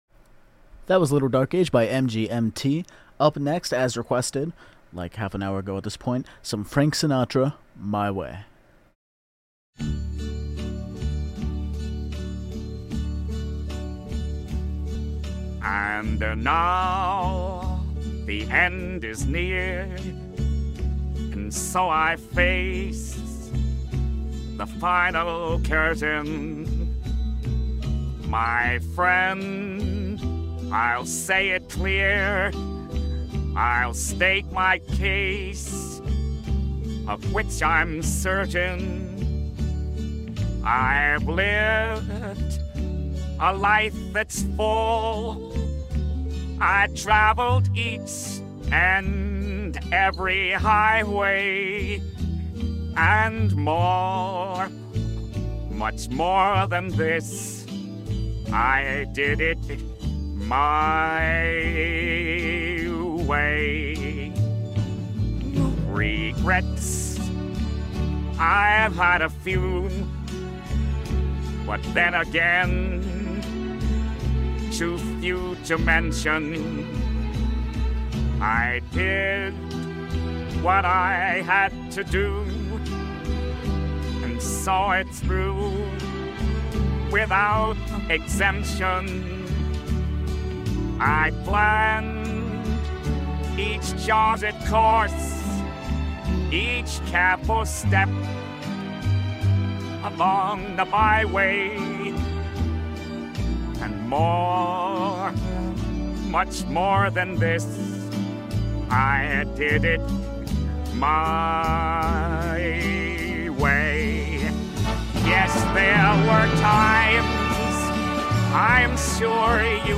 FrankSinatraOnMCORadio.mp3